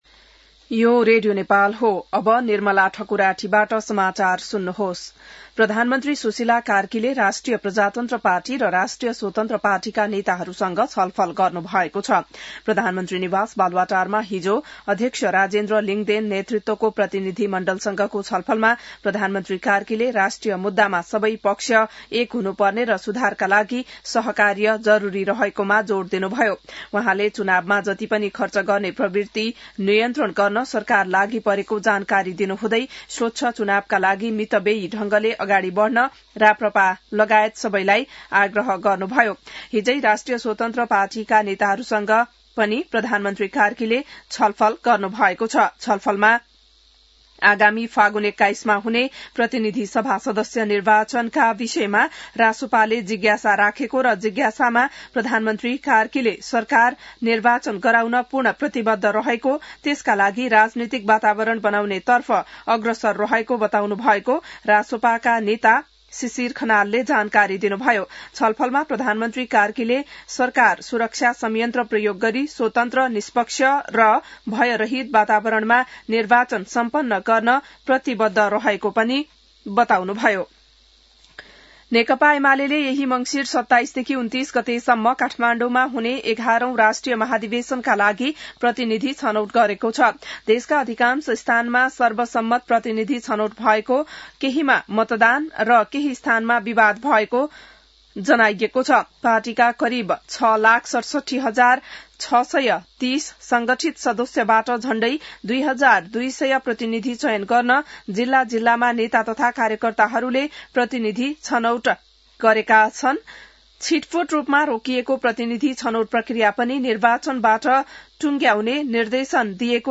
बिहान १० बजेको नेपाली समाचार : १४ मंसिर , २०८२